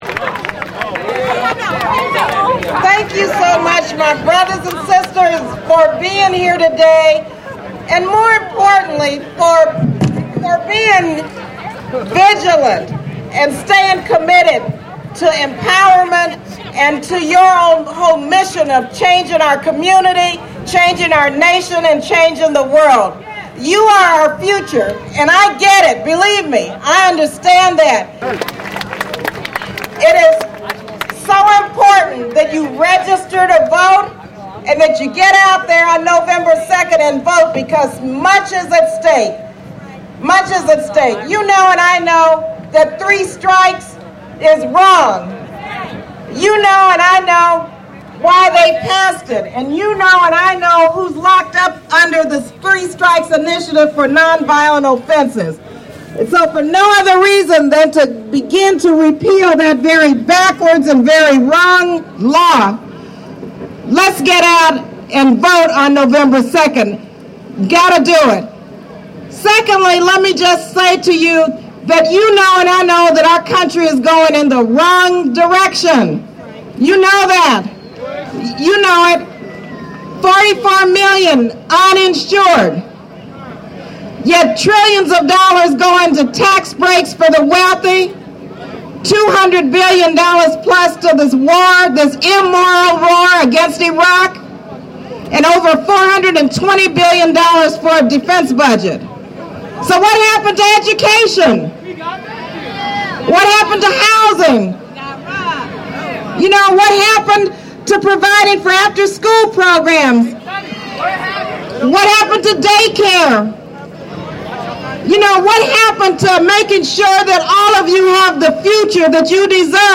Video of Congresswoman Barbara Lee rapping at the State of Emergency Hip Hop Summit in Oakland
a funky beat